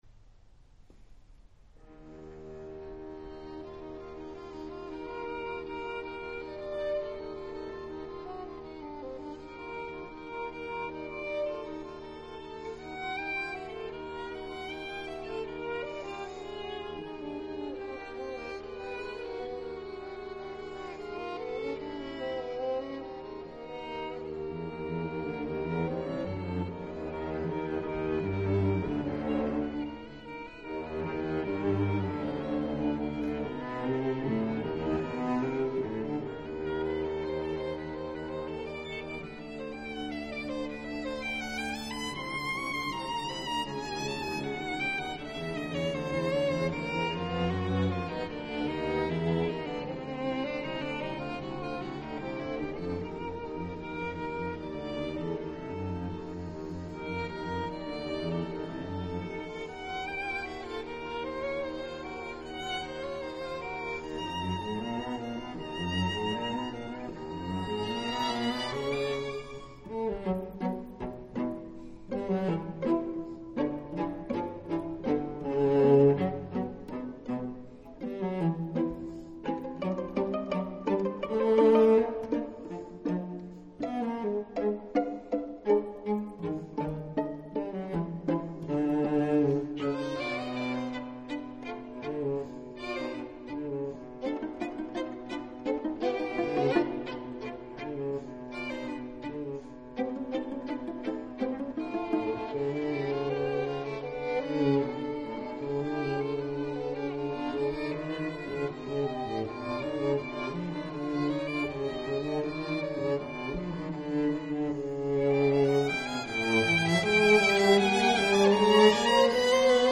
The Battery String Quartet
violin
viola
cello